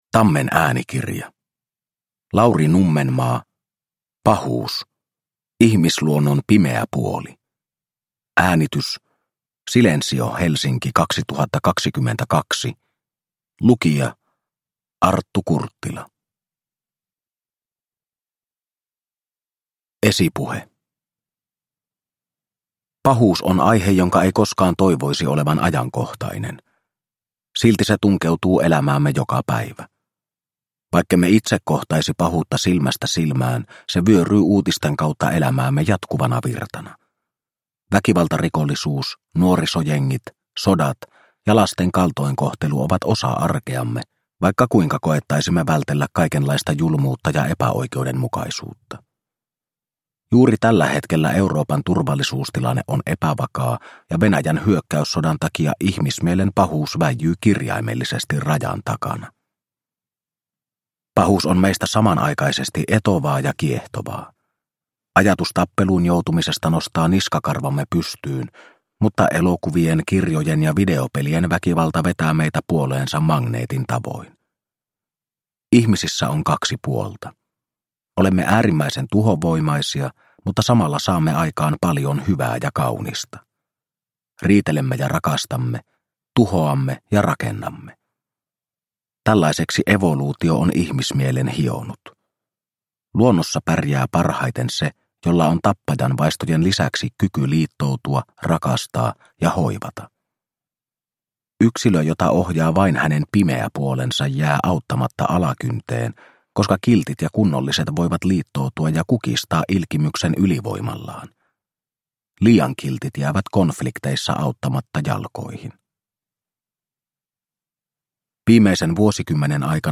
Pahuus – Ljudbok – Laddas ner